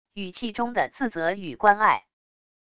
语气中的自责与关爱wav音频